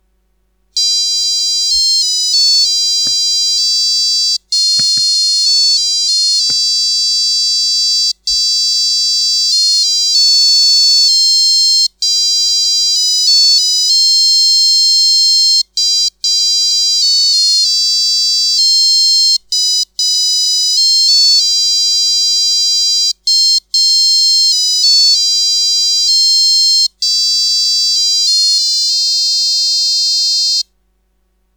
Мелодии в часах